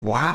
Wow Sound Effect